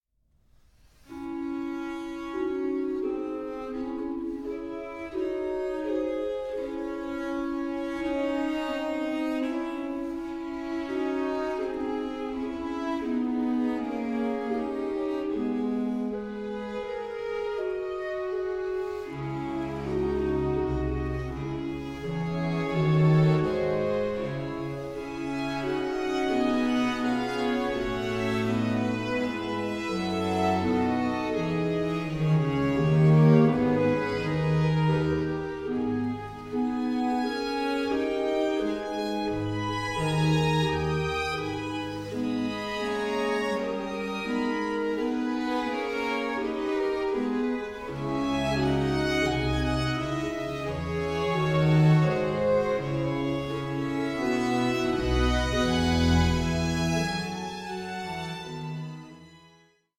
Concerto grosso in F Major, Op. 6 No. 6
Allegro 1:49